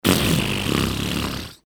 Gemafreie Sounds: Körpergeräusche
mf_SE-5887-bfrrr.mp3